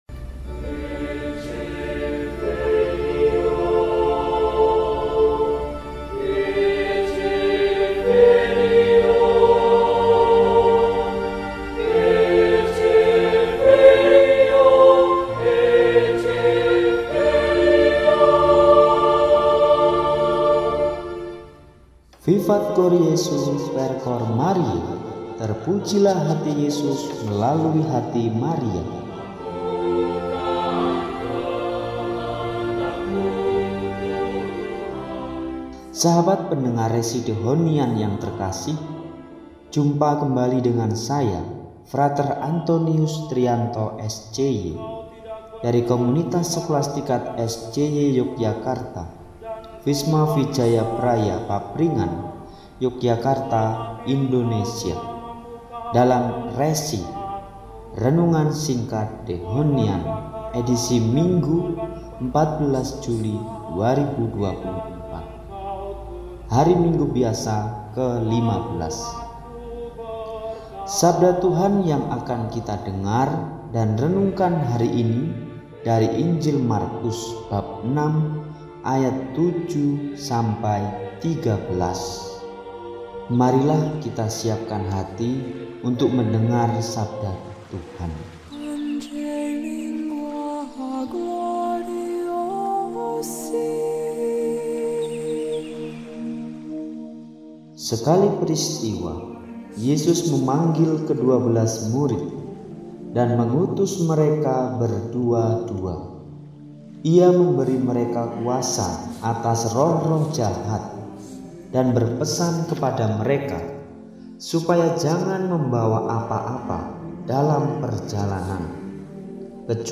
Minggu, 14 Juli 2024 – Hari Minggu Biasa XV – RESI (Renungan Singkat) DEHONIAN